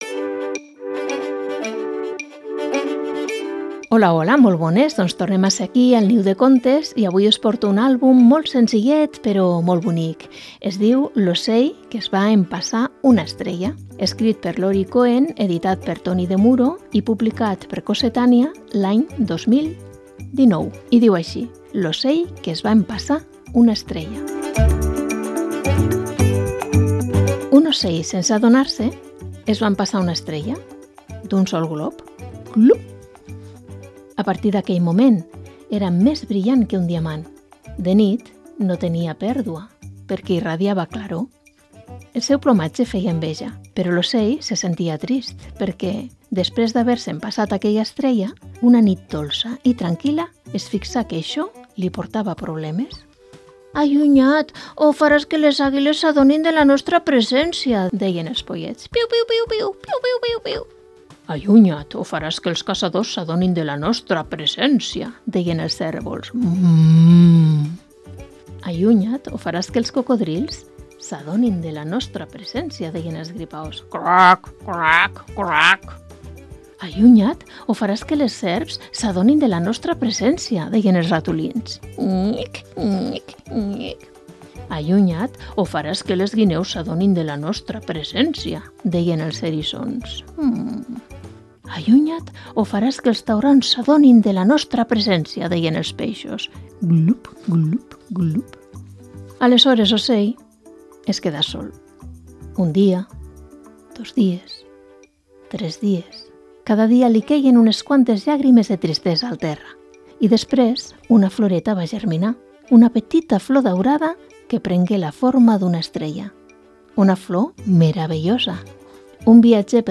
Programa de lectura de contes